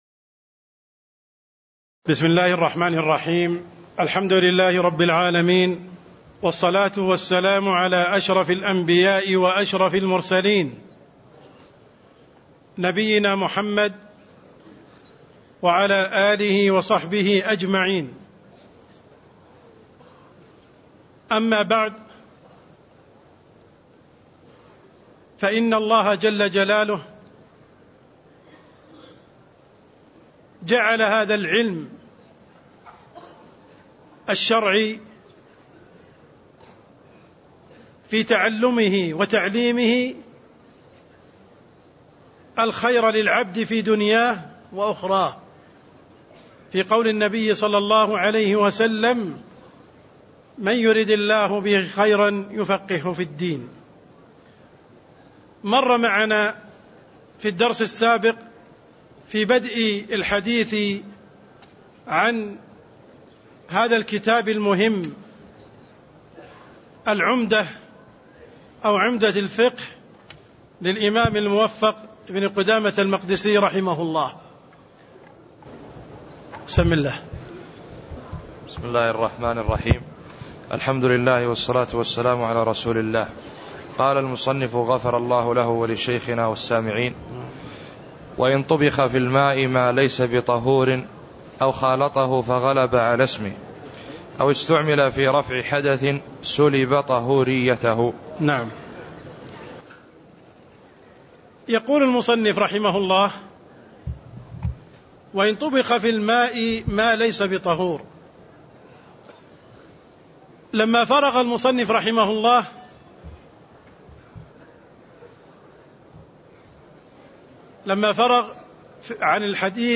تاريخ النشر ١٨ ربيع الثاني ١٤٣٥ هـ المكان: المسجد النبوي الشيخ: عبدالرحمن السند عبدالرحمن السند باب أحكام المياة (02)